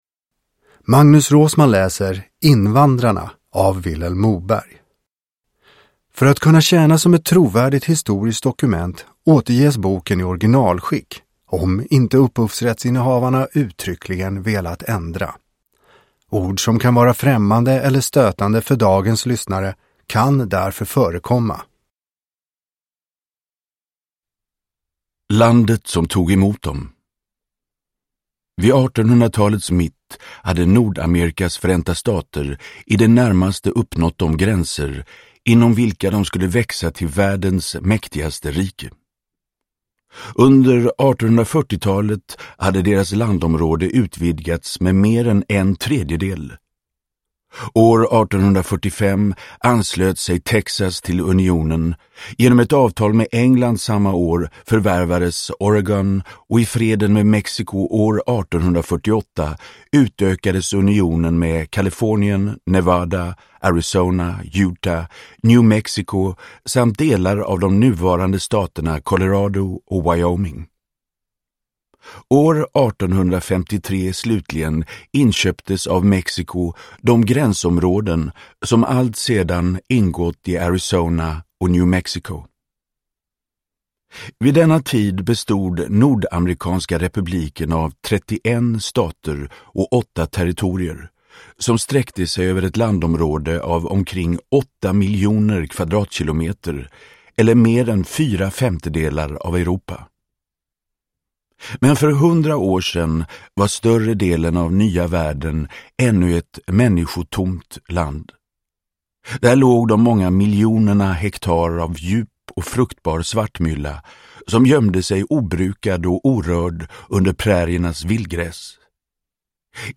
Invandrarna – Ljudbok – Laddas ner
Uppläsare: Magnus Roosmann